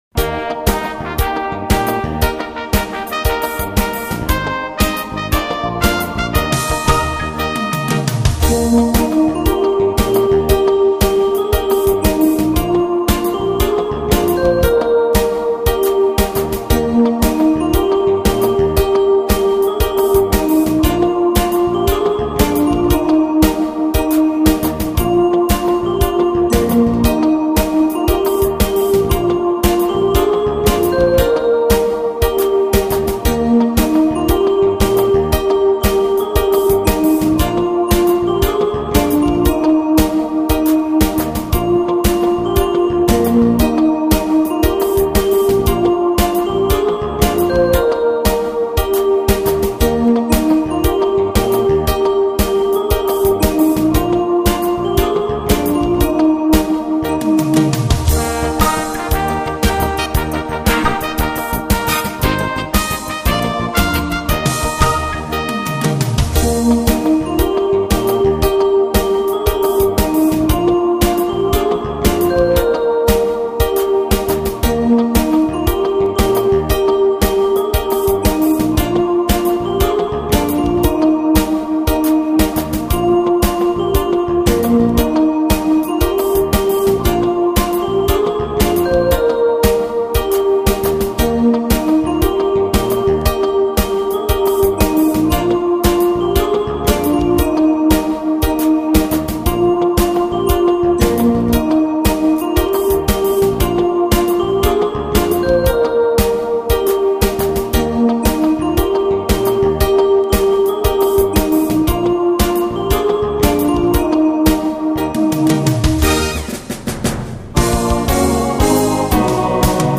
marsz_pulkowy.mp3